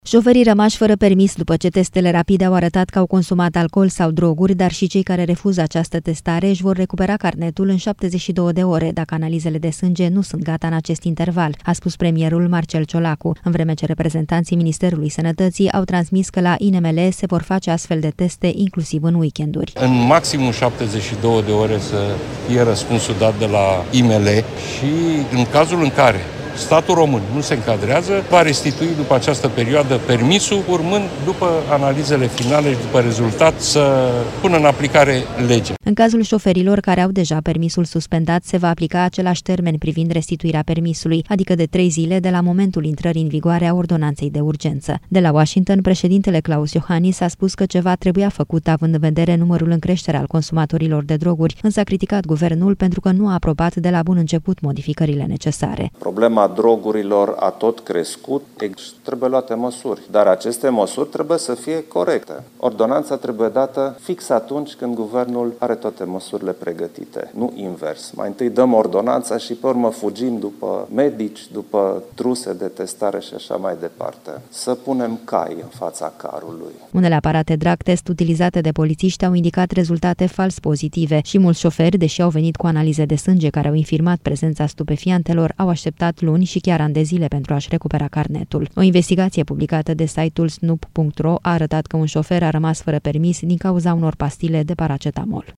Marcel Ciolacu: Legea va fi pusă în aplicare după rezultatul final al analizelor de sânge
Klaus Iohannis: „Ordonanța trebuie dată fix atunci când Guvernul are toate măsurile pregătite”